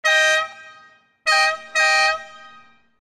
Звуки сигнала машины
На этой странице собраны разнообразные звуки автомобильных сигналов: от стандартных гудков до экстренных клаксонов.